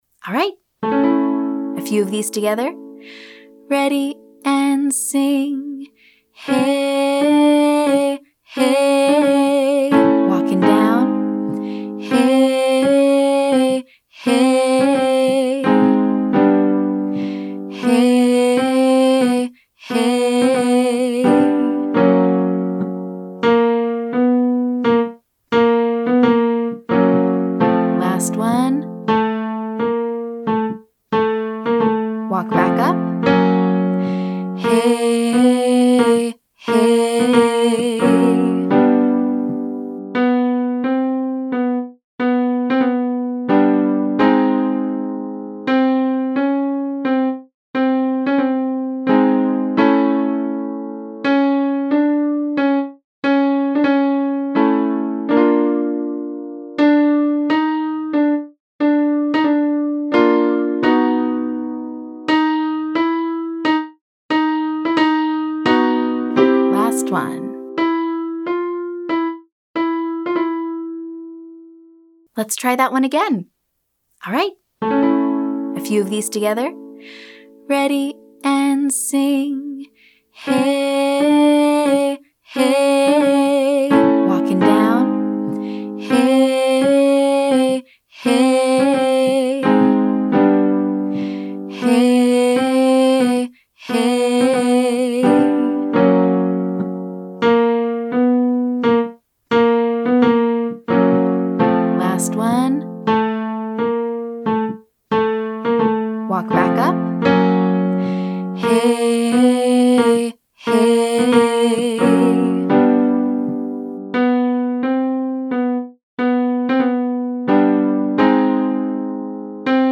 Pulses & Bends - Online Singing Lesson